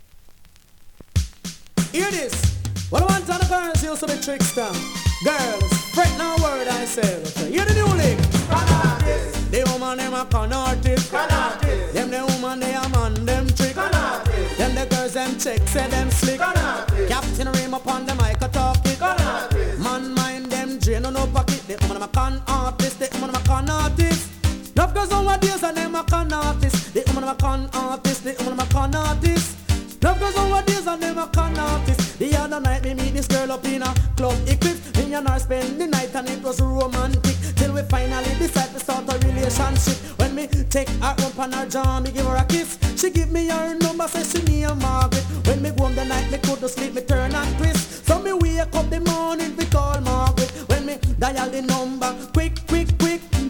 2026 NEW IN!! DANCEHALL!!
スリキズ、ノイズかなり少なめの